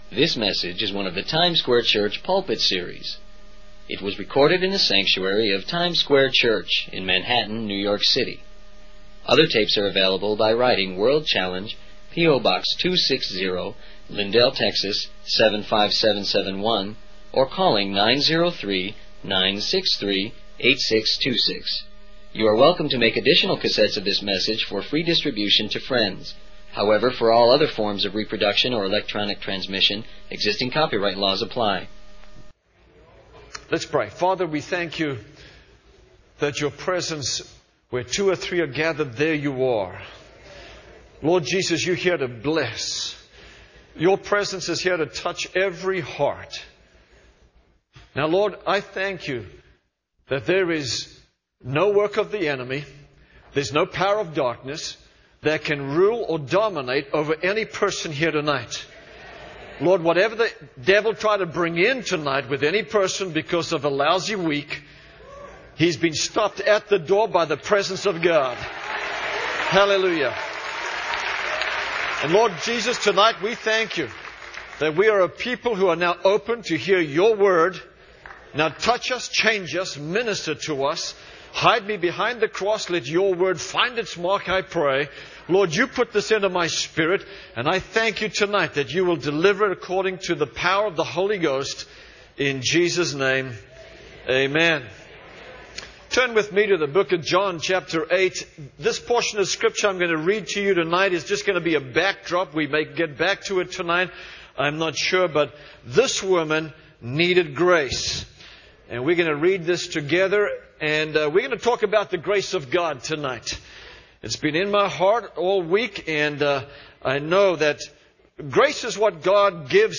He also discusses how grace can be found in difficult situations and encourages believers to turn to Jesus in times of trouble. The sermon references various Bible verses, including 2 Timothy 4:22 and 1 Peter 4:10, to illustrate the power of grace in the lives of believers.